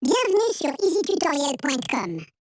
1. Changer la hauteur de l'audio
Voici le résultat de cette première méthode de changement de voix dans Audacity :
audio-change-hauteur.mp3